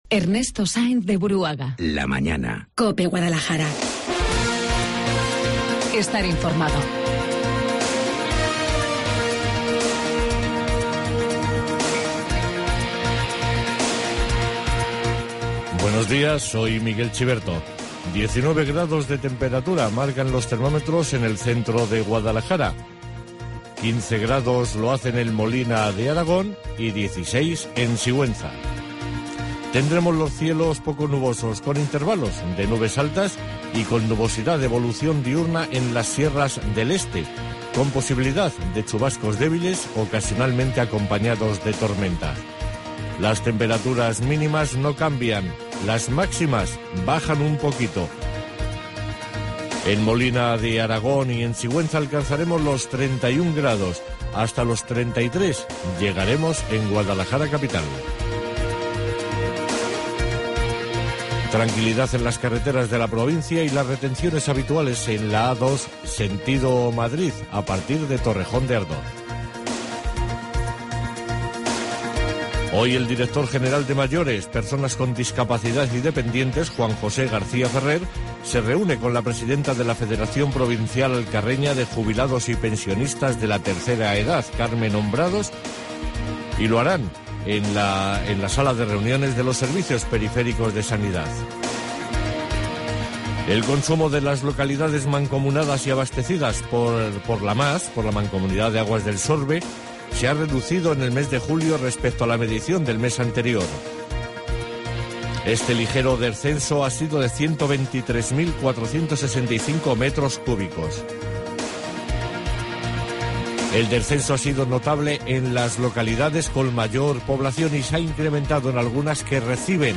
Informativo Guadalajara 6 DE AGOSTO